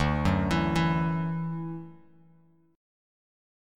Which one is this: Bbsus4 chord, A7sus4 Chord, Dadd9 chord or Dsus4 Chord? Dadd9 chord